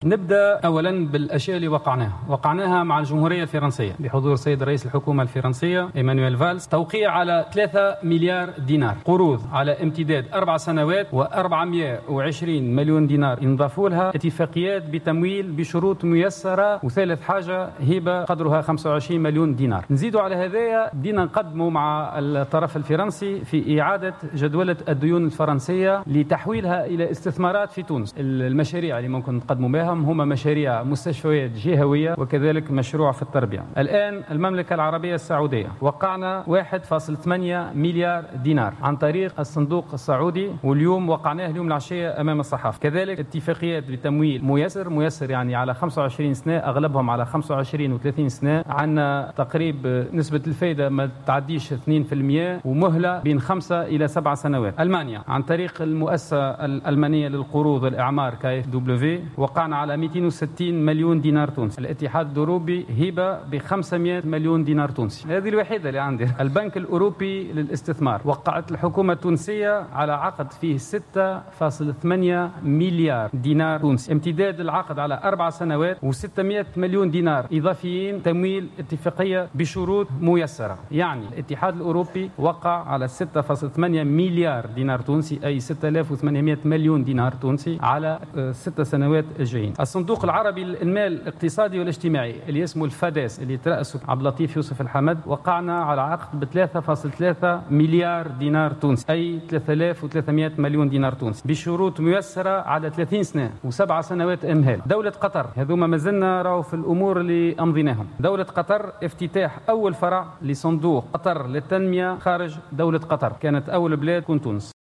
وأكد في ندوة صحفية بمناسبة اختتام مؤتمر تونس 2020 على ضرورة التمييز بين الوعود وبين التوقيع على اتفاقيات تمويل.